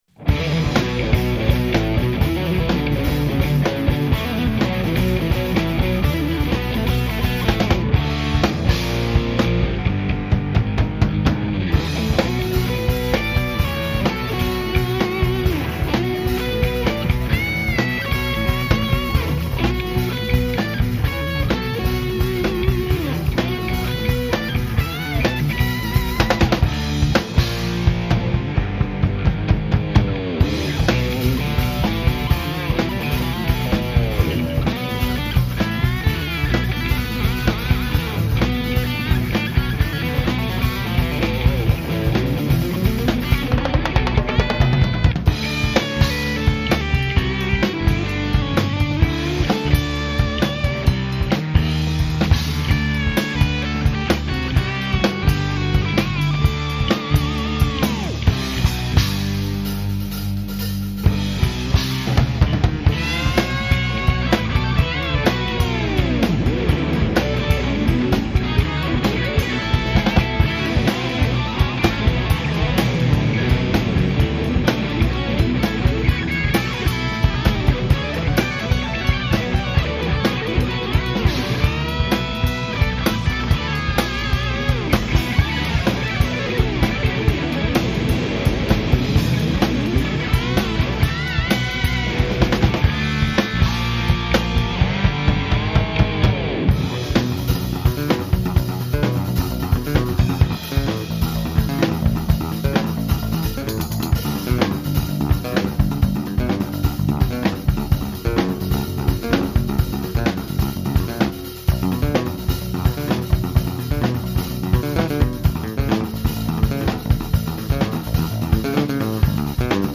Santa Cruz home studio recordings  (1999)